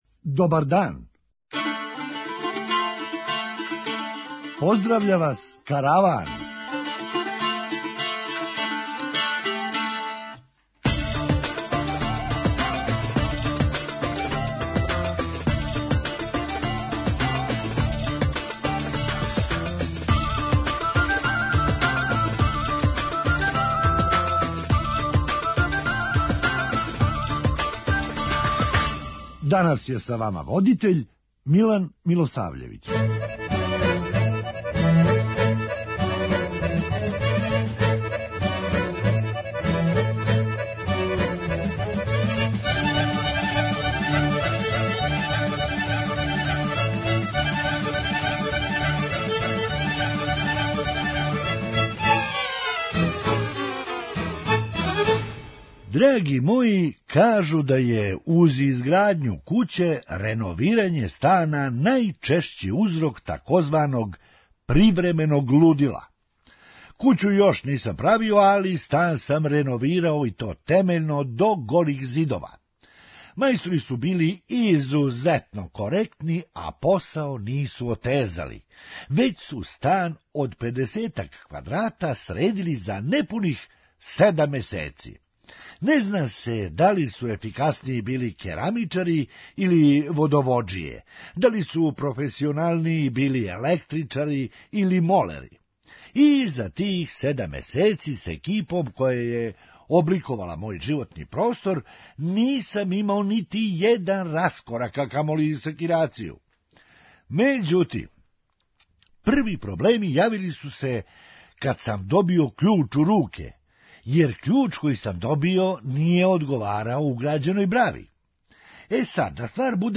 Хумористичка емисија
То значи да се у овом тренутку Србија налази на ничијој земљи. преузми : 8.92 MB Караван Autor: Забавна редакција Радио Бeограда 1 Караван се креће ка својој дестинацији већ више од 50 година, увек добро натоварен актуелним хумором и изворним народним песмама.